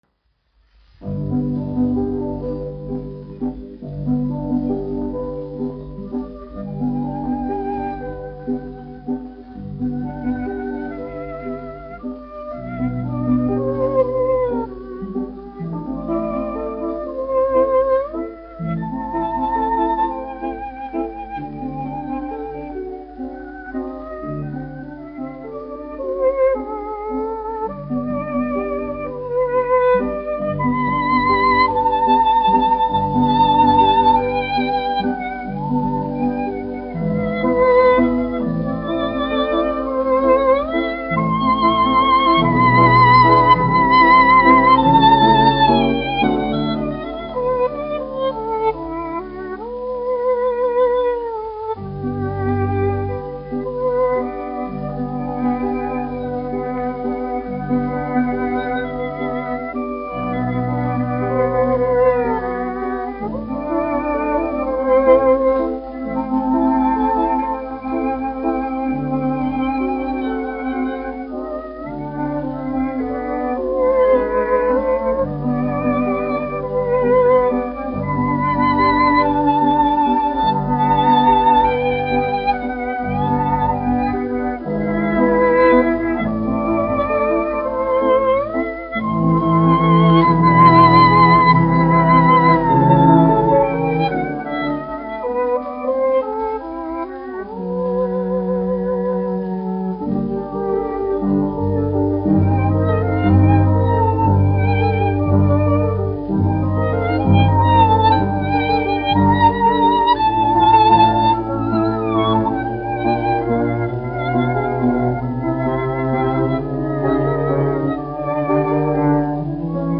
1 skpl. : analogs, 78 apgr/min, mono ; 25 cm
Orķestra mūzika, aranžējumi
Latvijas vēsturiskie šellaka skaņuplašu ieraksti (Kolekcija)